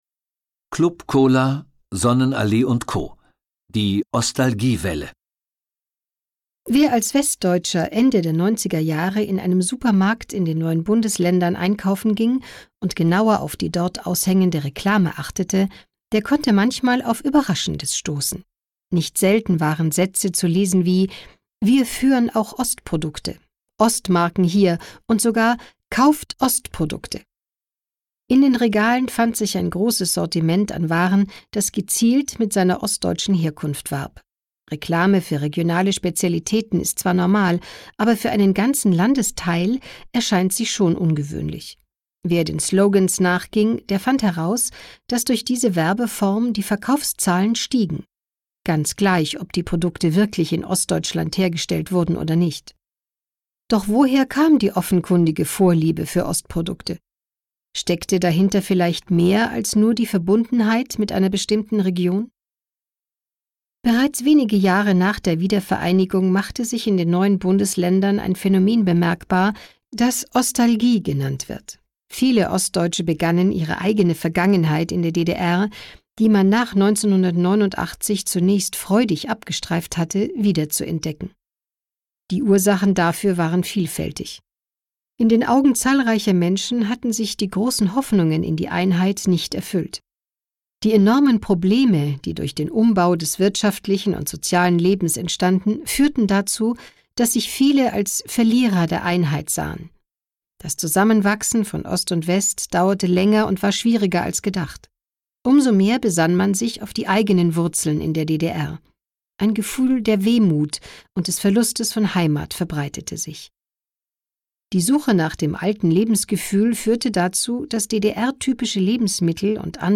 Die Wiedervereinigung Deutschlands wird rechtlich zwar bereits 1990 voll zogen, in den Köpfen vieler Menschen ist die Mauer jedoch immer noch präsent. Welche Probleme zu überwinden sind und welche Chancen sich bieten, erzählt dieses Hörbuch.